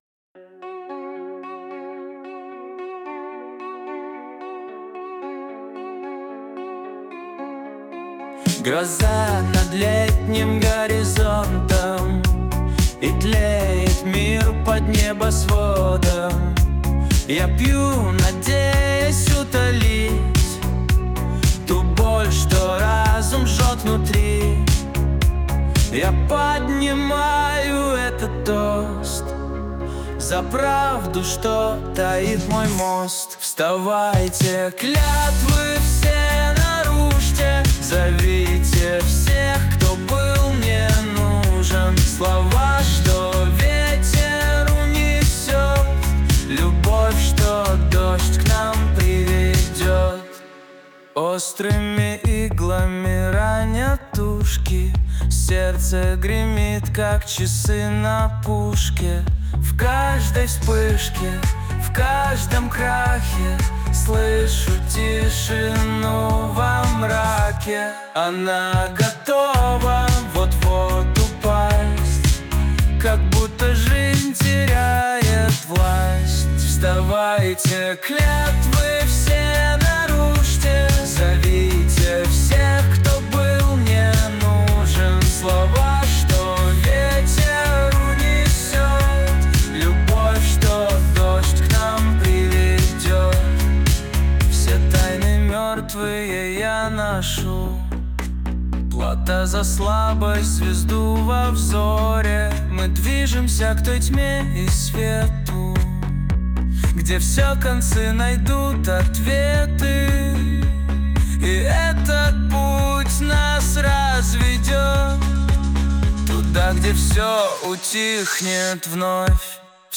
RUS, Dance, Pop, Disco | 16.03.2025 09:16